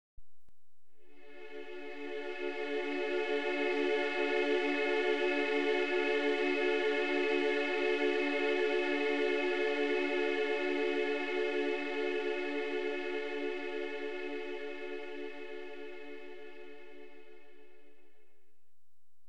D mit 288 Hz und 432 Hz Kammerton,
mit Schwebung im Theta Bereich,
inklusive grosser Terz und Quinte
D288HZ_Freude.wav